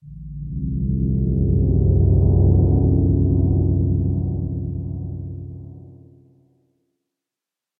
sounds / ambient / cave / cave3.mp3
cave3.mp3